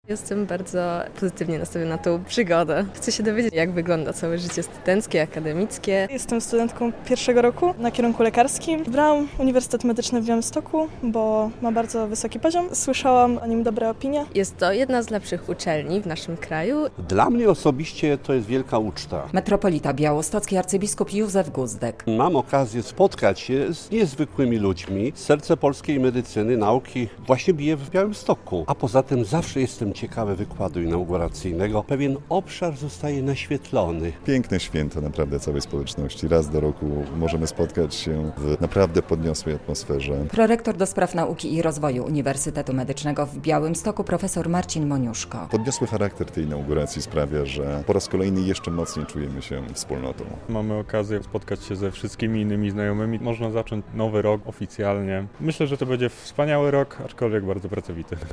Uniwersytet Medyczny w Białymstoku, jedna z najlepszych uczelni w Polsce, uroczyście zainaugurował w poniedziałek (02.10) nowy rok akademicki. Okolicznościowe przemówienia i wykład inauguracyjny odbyły się w gmachu Opery i Filharmonii Podlaskiej.